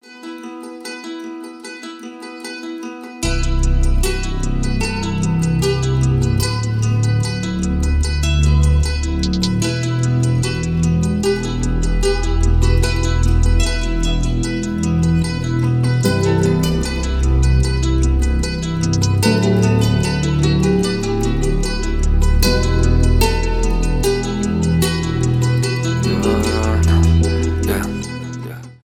без слов
битовые
рэп
инструментальные